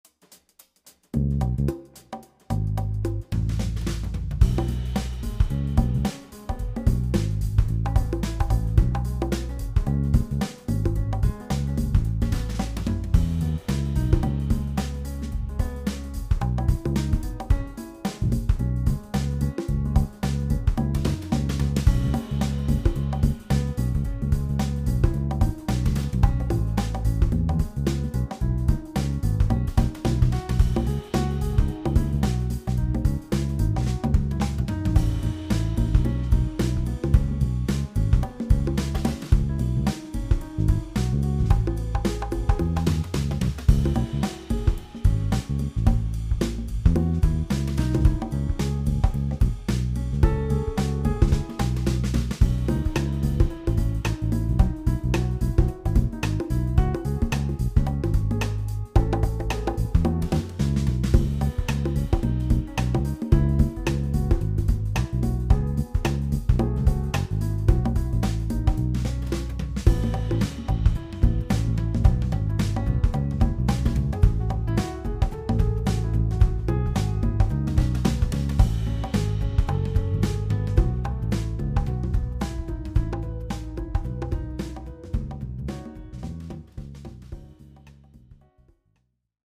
【声劇台本】友達作り【二人用】Downtown old bar Original Instrumental